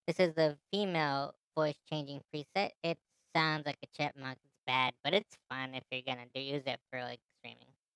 The SC3 comes with a voice changer feature.
Female Preset
femalevoicechange.mp3